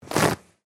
На этой странице собраны звуки, характерные для общественных туалетов: журчание воды, работа сантехники, эхо шагов по кафелю и другие бытовые шумы.
Шорох отрыва туалетной бумаги